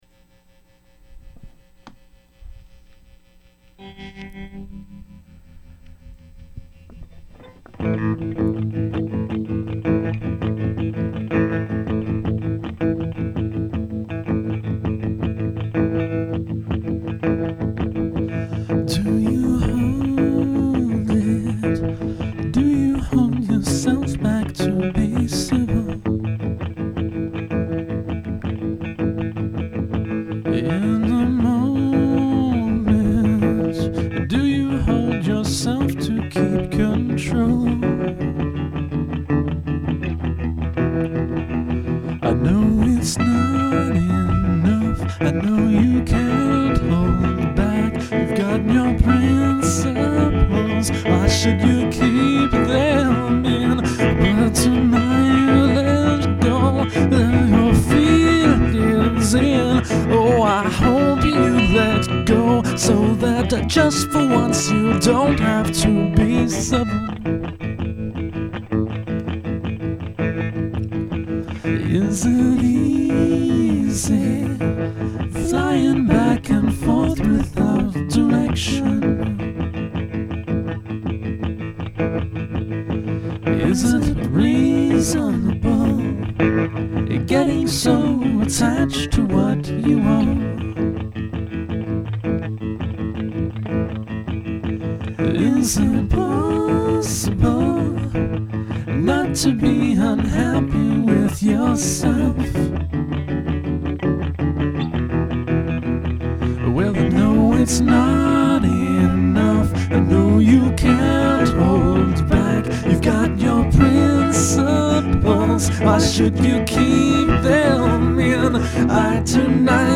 That song was a little quieter, the other one requires a big rude guitar solo and I can't get loud because of the next door dude's sleeping kid on thursdays.
A little thin, but this is to be expected. Your use of the secondary guitar line as a counterpoint to the main melody is at a high level these days.
I've been really enjoying the dual-guitar and singing vibe though - it holds up well, and doubly so when noise/recording kinks work out.